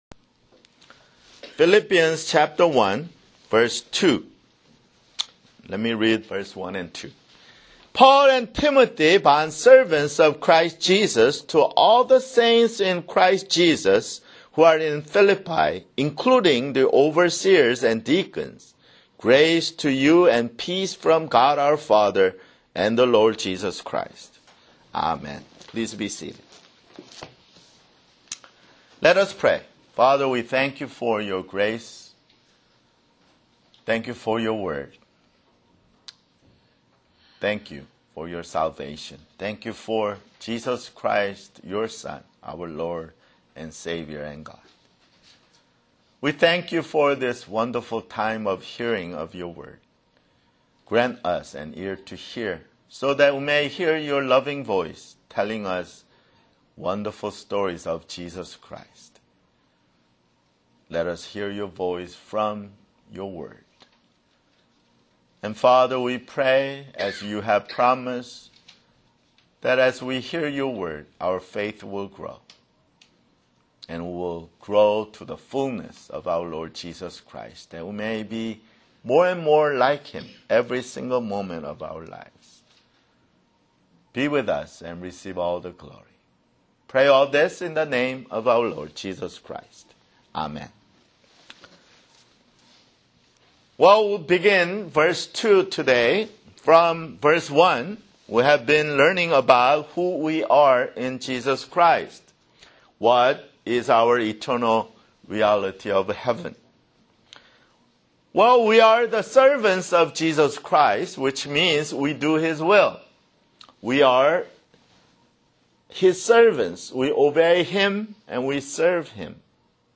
[Sermon] Philippians (6)